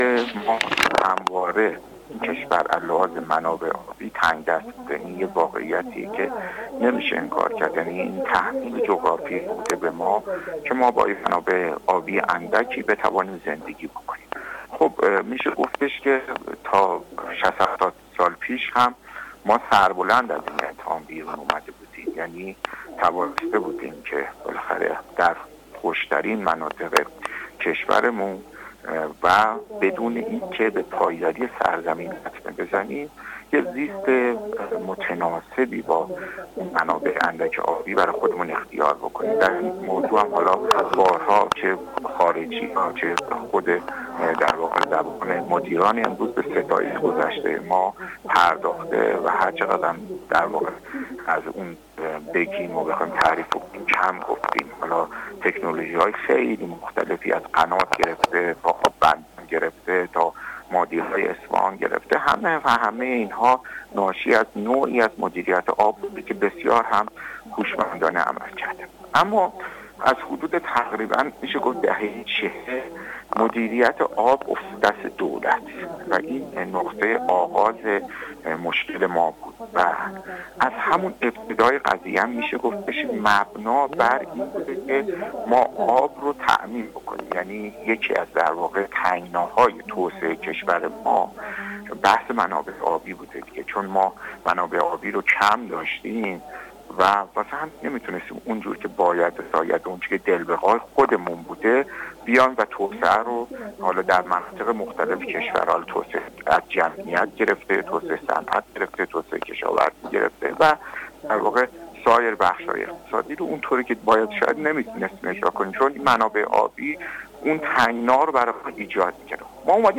در گفت‌وگو با ایکنا